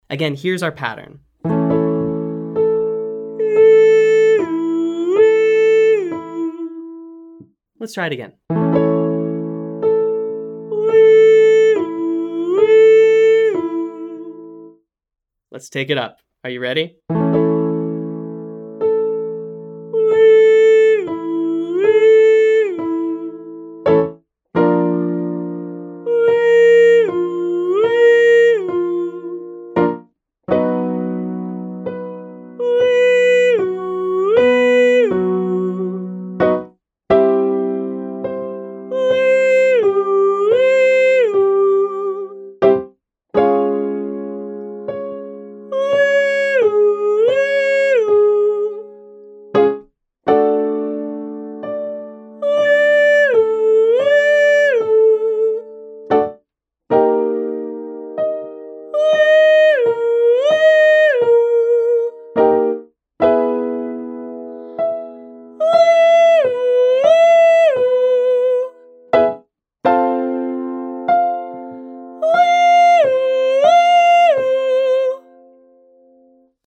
Exercise: Excited “whoo-hoo”  E
The exercise is just approximating, or getting close to, the pitch I play on the piano.